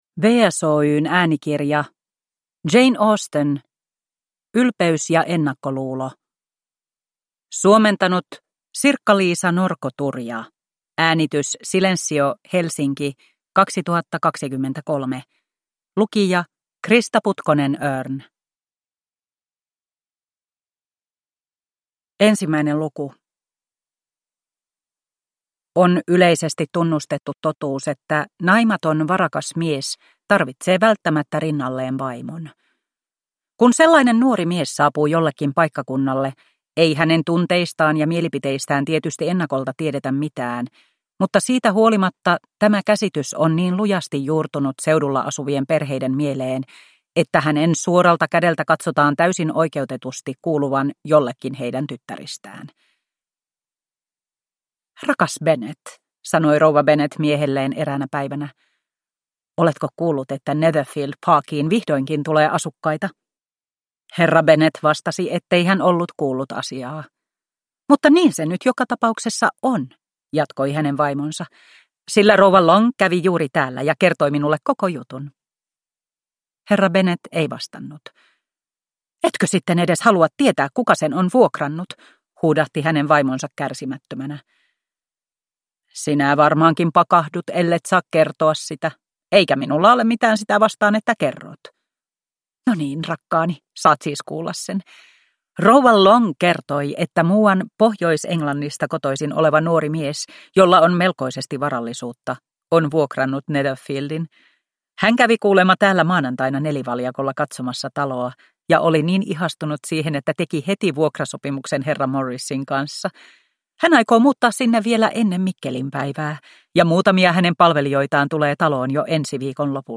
Ylpeys ja ennakkoluulo – Ljudbok